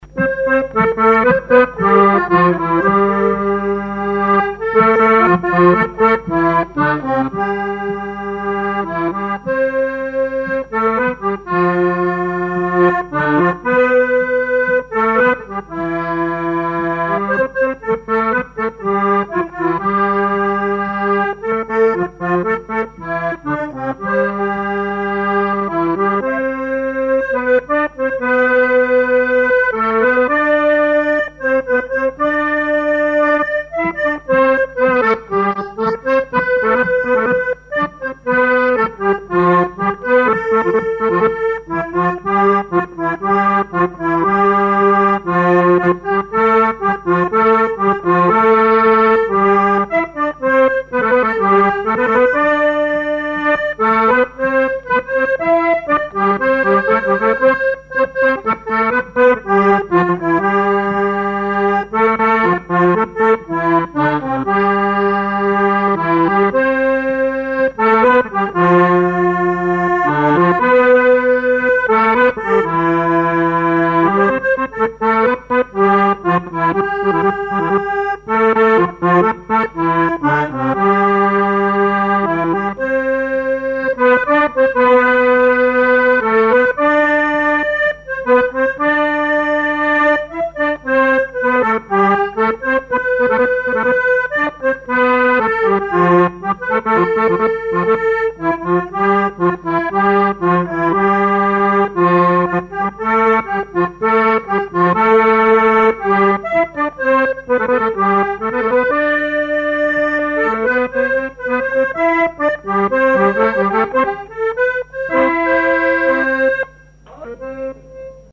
danse : java
collectif de musiciens pour une animation à Sigournais
Pièce musicale inédite